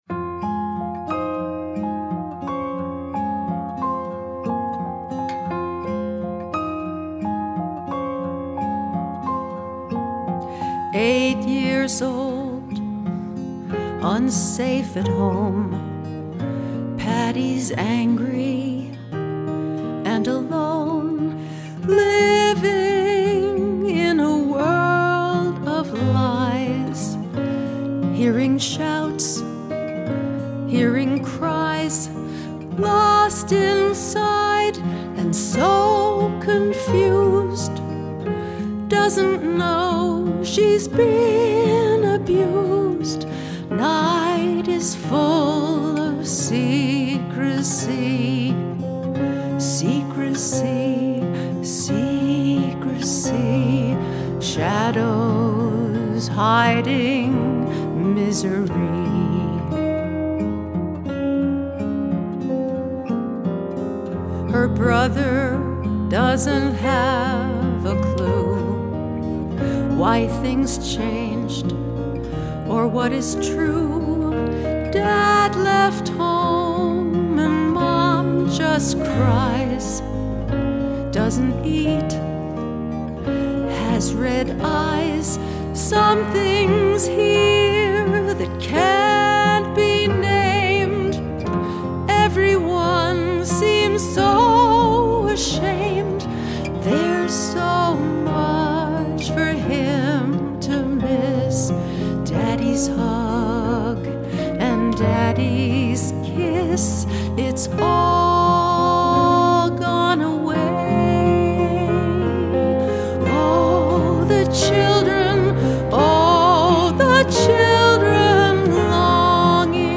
Be forewarned: there is nothing cheerful in this song.
But your voice is beautiful.